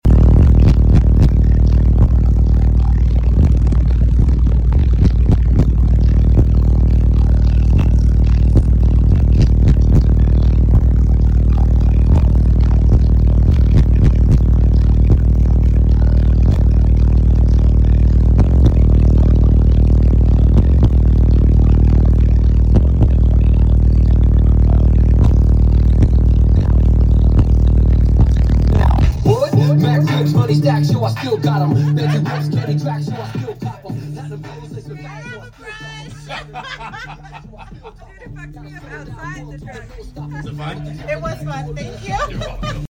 24 DC Audio XL Elite 12s on 80,000 watts at 20hz giving her a windtunnel hair style.